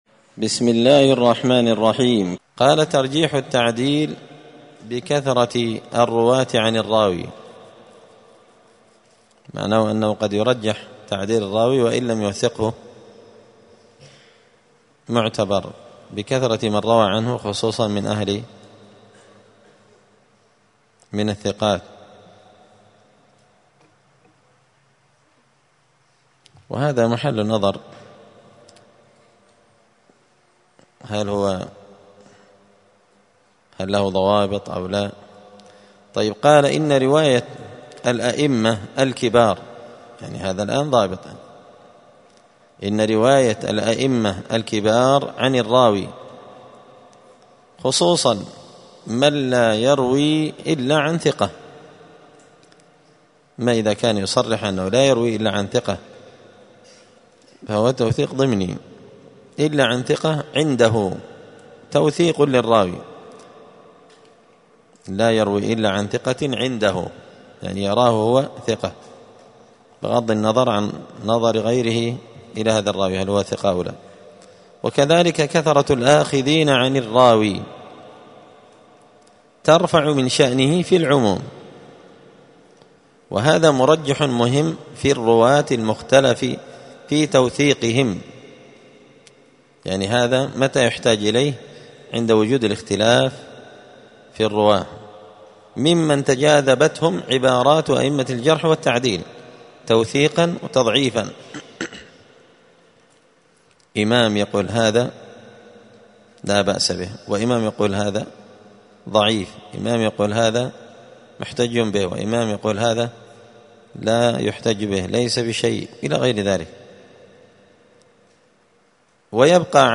*الدرس السادس والثلاثون (36) باب ترجيح التعديل لكثرة الرواة عن الراوي*